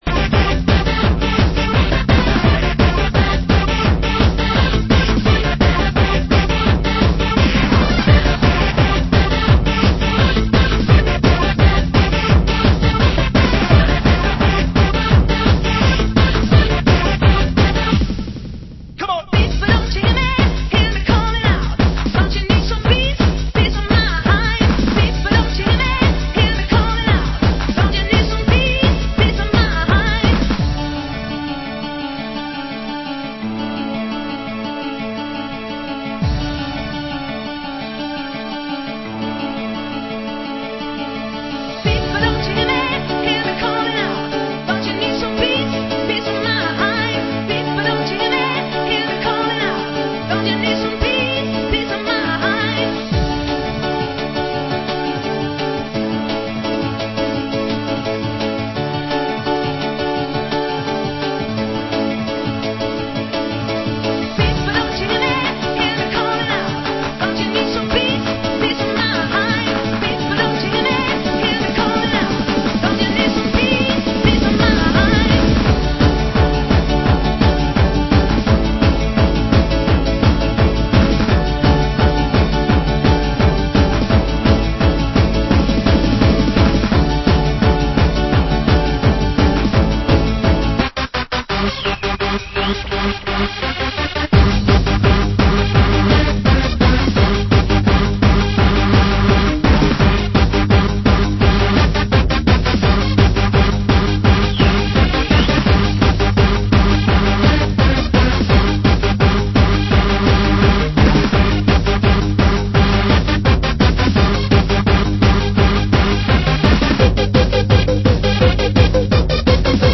Genre: Happy Hardcore